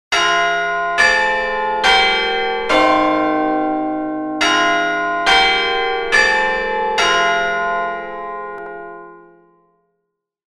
I used a gong sound for the hours and Big Ben chimes at the very end.